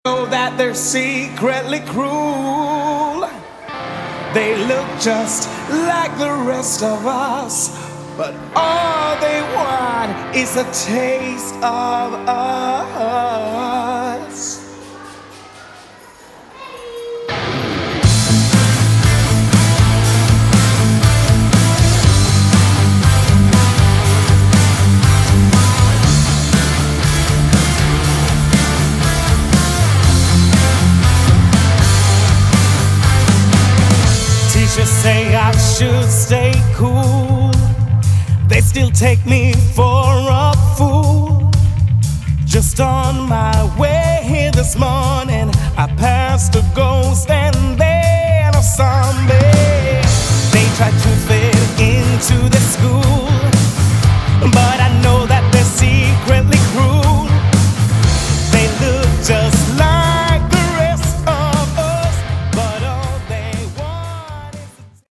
Category: Hard Rock
lead vocals
bass
guitar
drums